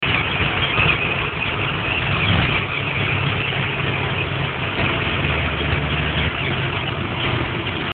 Dazu kommt ja, dass das Geräusch in der Frequenz nicht Geschwindigkeitsabhängig ist sondern einfach nur verschwindet wenn man zu langsam fährt!
Hab heute auch mal das Handy rausgehangen:
wie gesagt, Möwen...
e32moewen.mp3